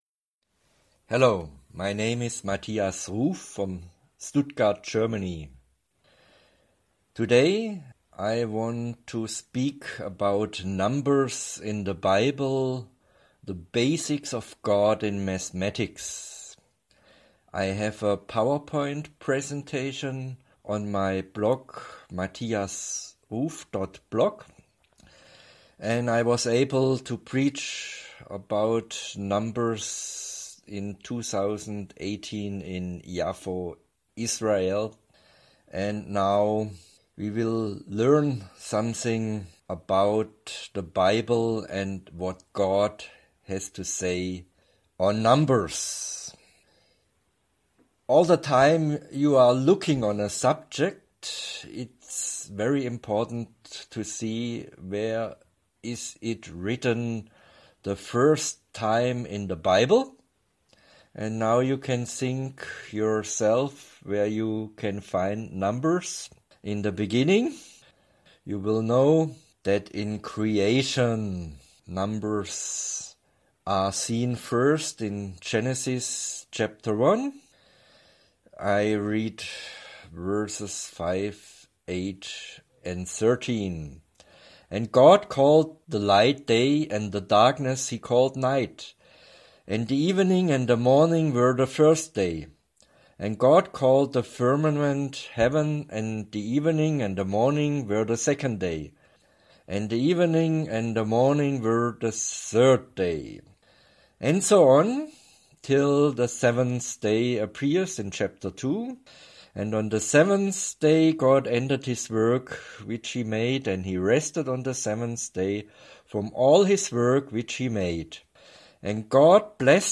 MP3 voice recording of the presentation for listening or to download: